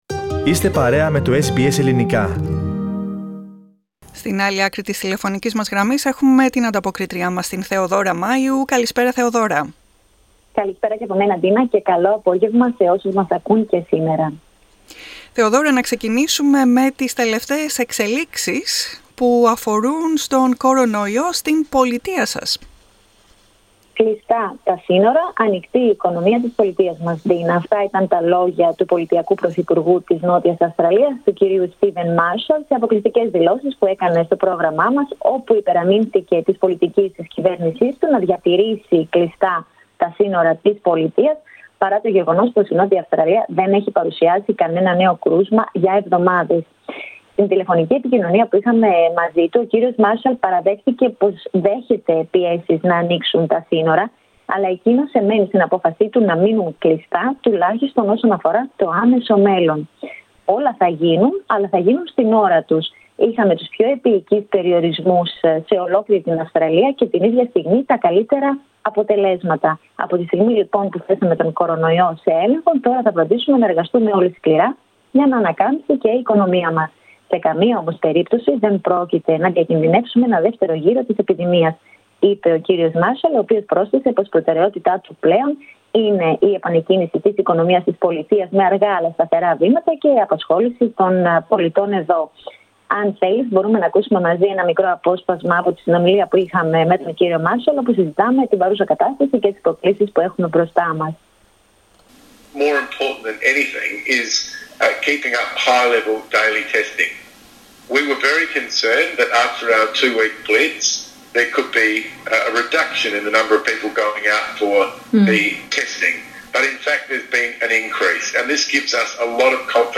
Αν θέλεις μπορούμε να ακούσουμε ένα μικρό απόσπασμα από την συνομιλία που είχαμε με τον πολιτειακό πρωθυπουργό όπου συζητάμε την παρούσα κατάσταση και τις προκλήσεις που έχουμε μπροστά μας.